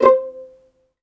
VIOLINP DN-R.wav